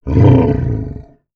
MONSTER_Growl_Medium_13_mono.wav